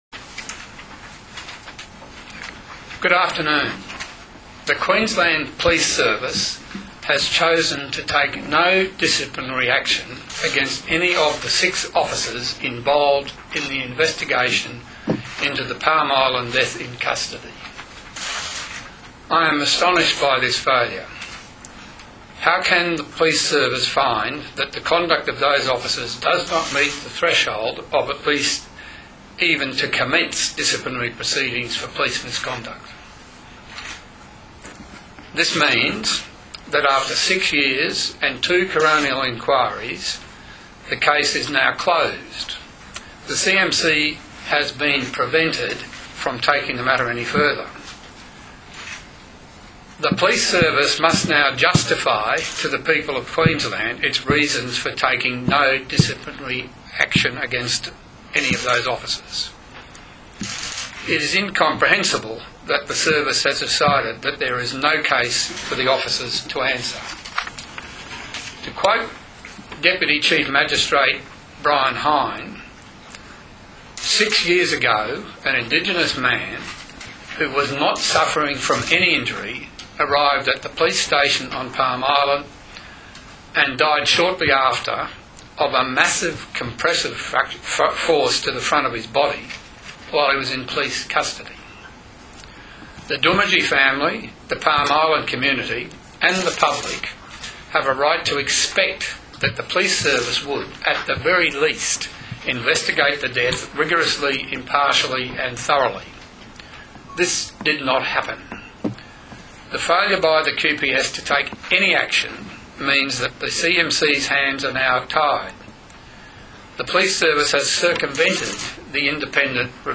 Statement by Crime and Misconduct Commission Chairperson Martin Moynihan AO QC